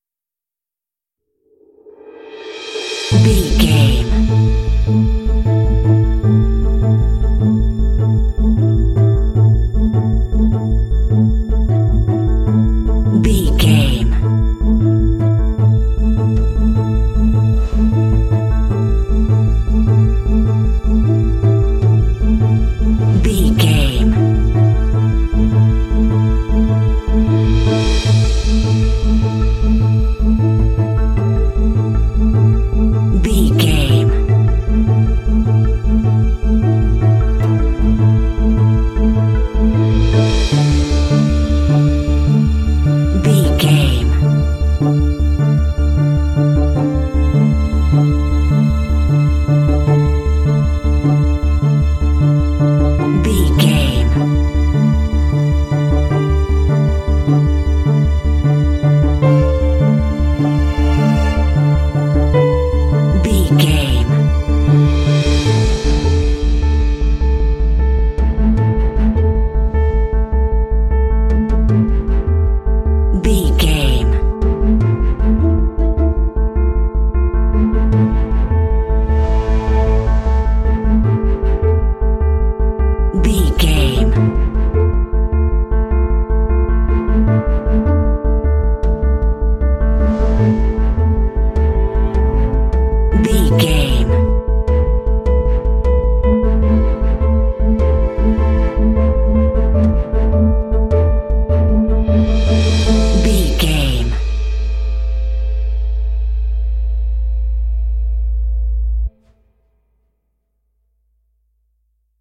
Aeolian/Minor
tension
suspense
dramatic
contemplative
drums
piano
strings
synthesiser
cinematic
film score